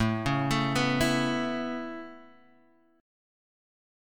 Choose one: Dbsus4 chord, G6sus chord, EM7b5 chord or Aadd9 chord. Aadd9 chord